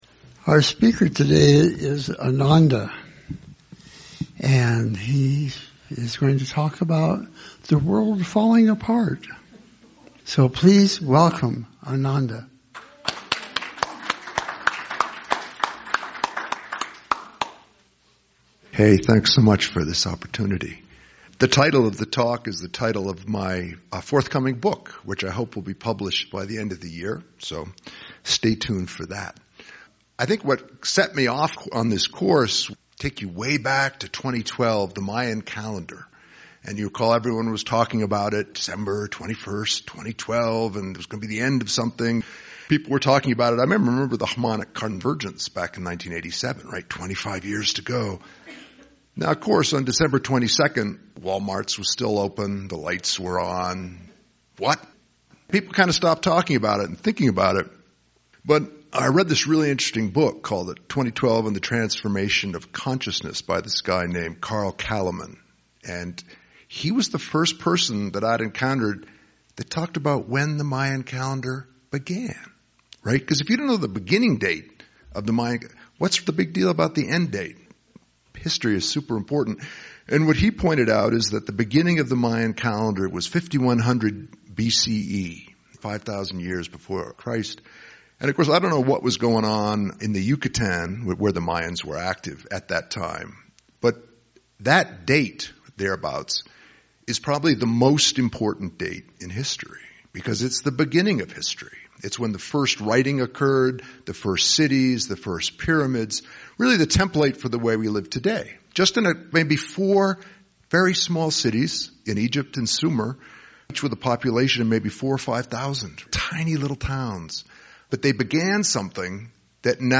Talk given at the Celebration in Santa Fe, NM in June, 2024 about the transformational crises the world is in now